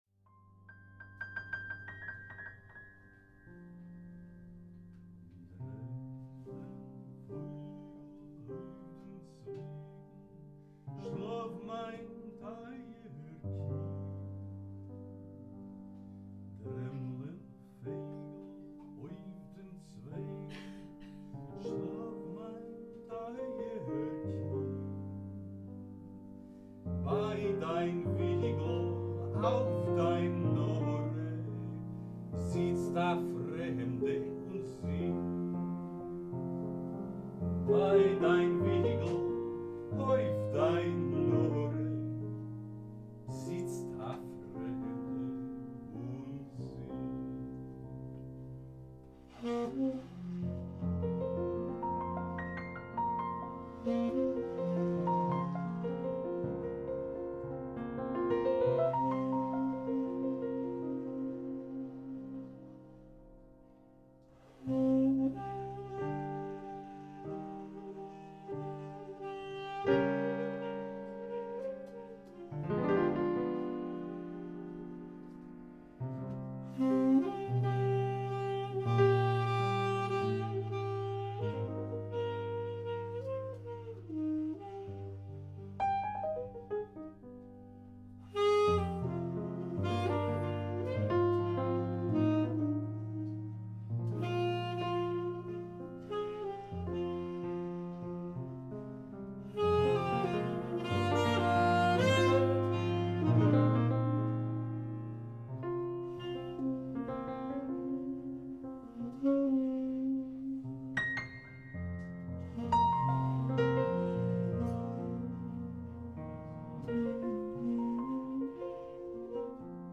It´s about vibes, it´s about swing, it´s Jazz.